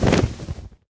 wings2.ogg